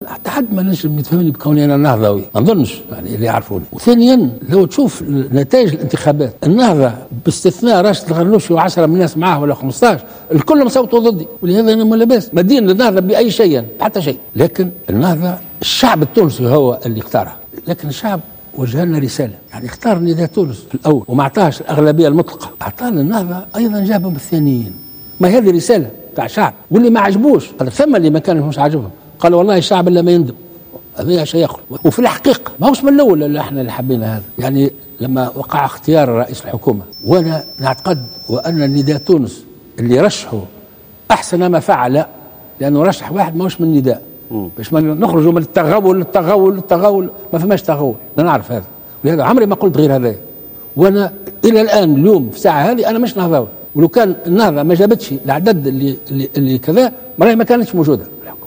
أكد رئيس الجمهورية الباجي قايد السبسي في مقابلة صحفية مع التلفزيون الرسمي "وطنية" مساء اليوم الأربعاء أنه "ليس نهضاويا إلى حد الآن".